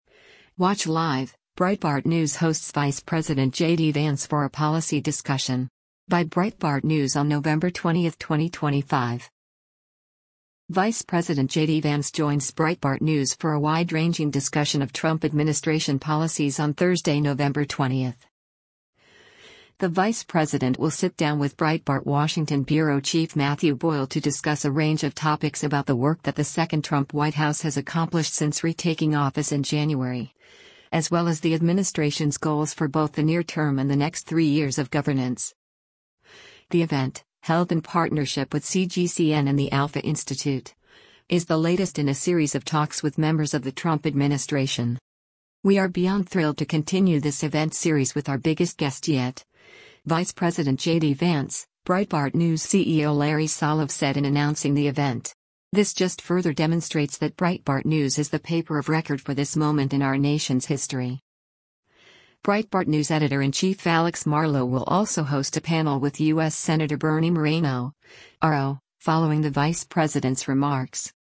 The event, held in partnership with CGCN and the ALFA Institute, is the latest in a series of talks with members of the Trump administration.